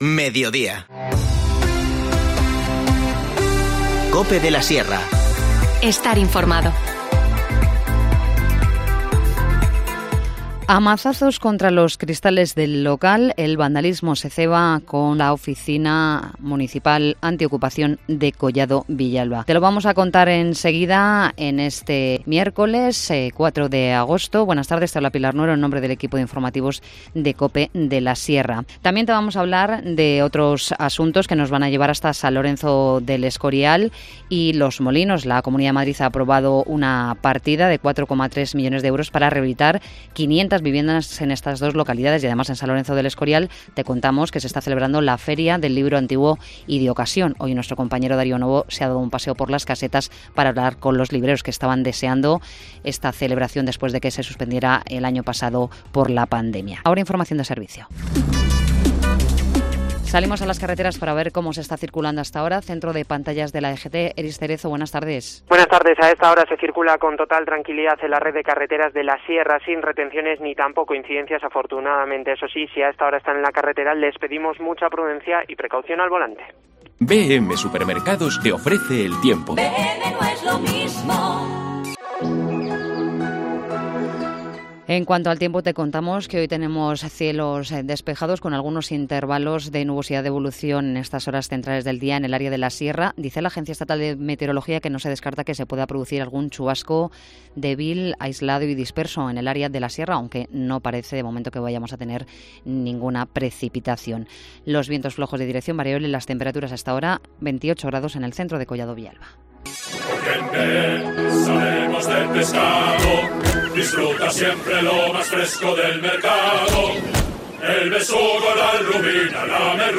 Informativo Mediodía 4 agosto